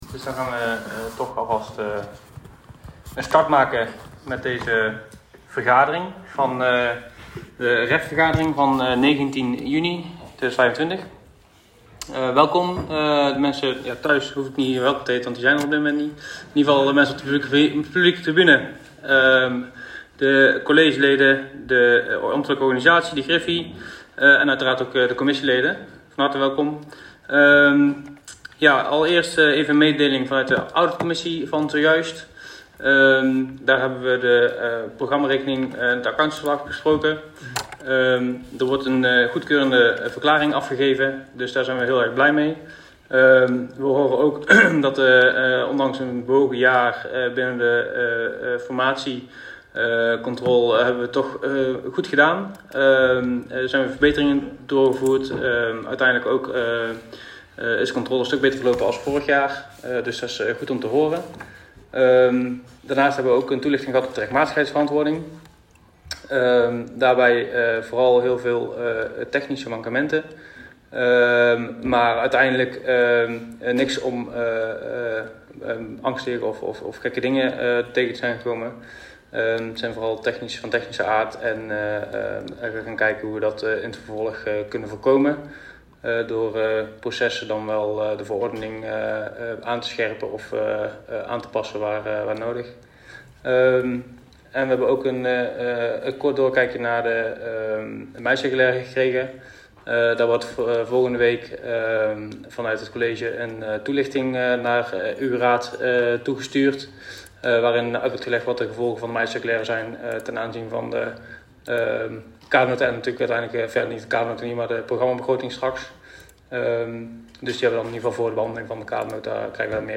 Agendabundel 218 MB Voorbereiding en orde commissievergaderingen 37 KB Geluidsfragment vergadering REF 19 juni 2025 (tot start video-opname) 11 MB Vastgestelde besluitenlijst Commissievergadering Ruimte Economie en Financiën 19 juni 2025 43 KB